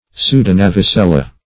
Search Result for " pseudonavicella" : The Collaborative International Dictionary of English v.0.48: Pseudonavicella \Pseu`do*nav`i*cel"la\, n.; pl.
pseudonavicella.mp3